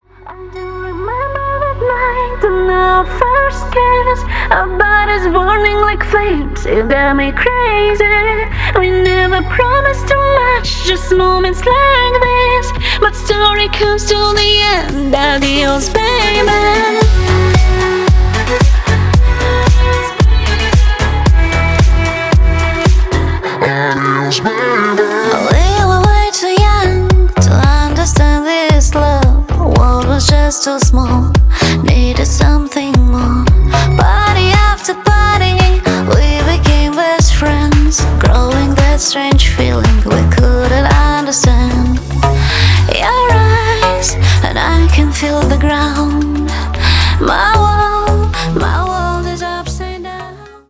• Качество: 320, Stereo
громкие
заводные
dance
Electronic
красивый женский голос
Стиль: electronic , dance.